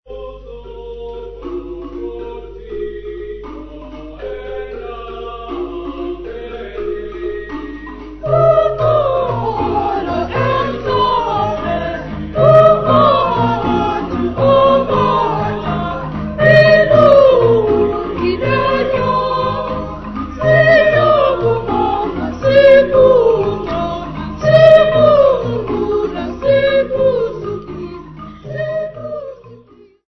Choral music
Field recordings
Africa South Africa Gugulethu f-sa
Indigenous music.
96000Hz 24Bit Stereo